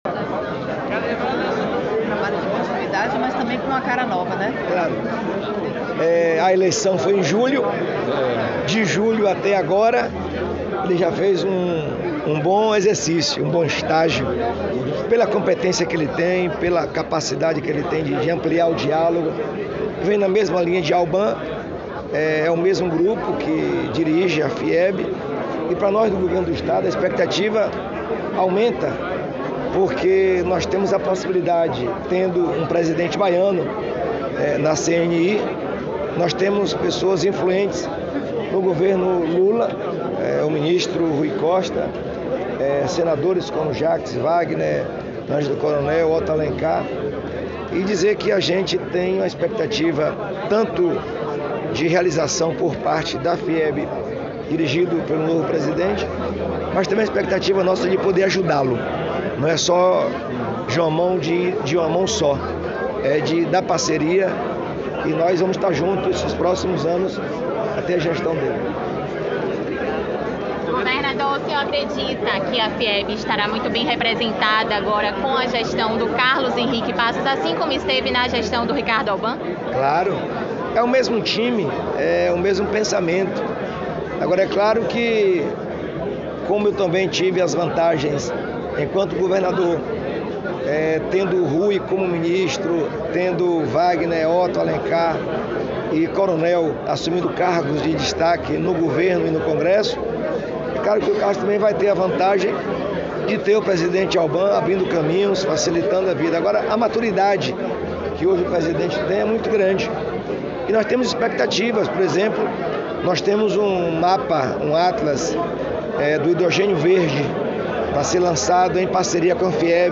🎙 Jerônimo Rodrigues – Governador da Bahia